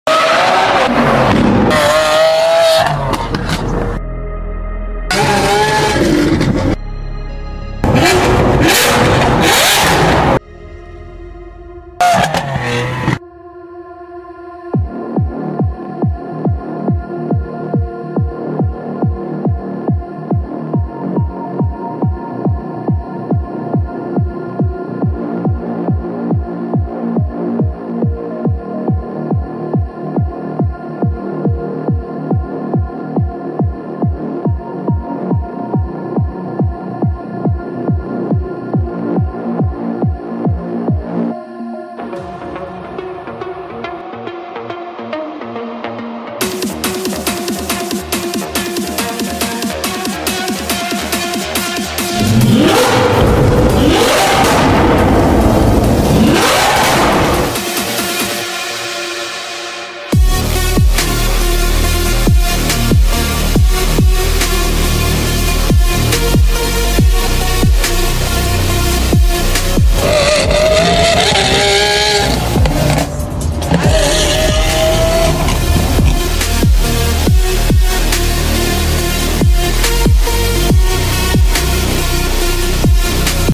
Part 4 | Loud SVJ Sound Effects Free Download